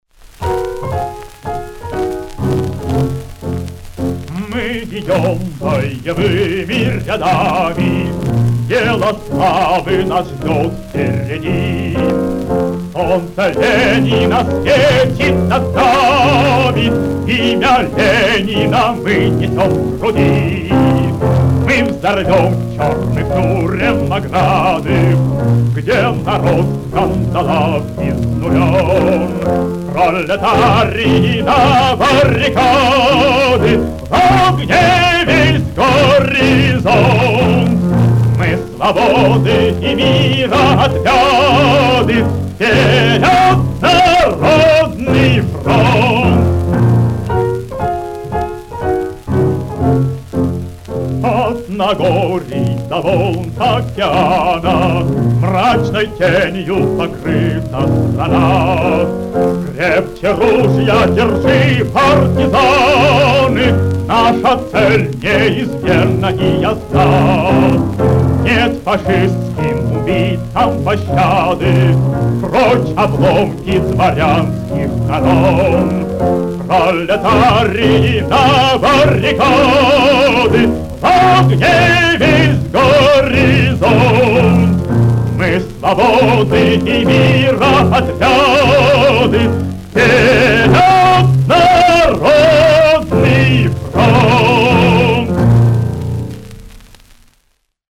Испанская революционная песня. Запись с редкой пластинки
ф-но